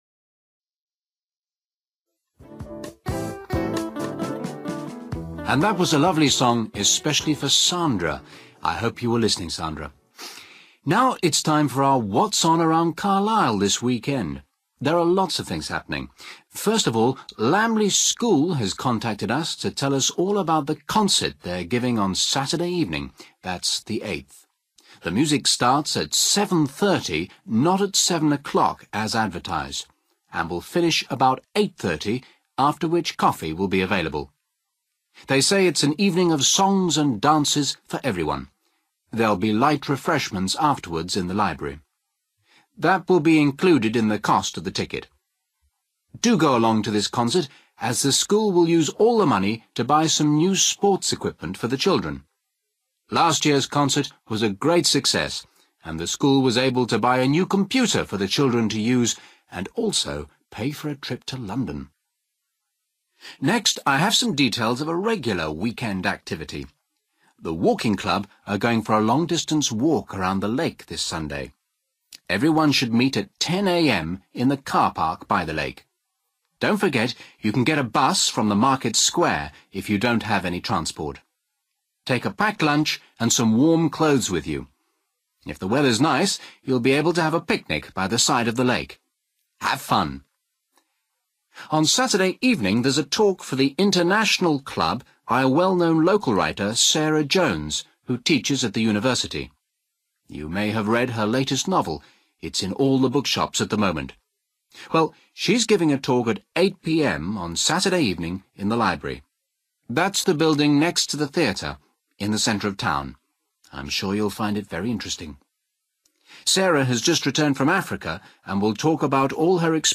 You will hear a radio presenter talking about weekend events in the Carlisle area.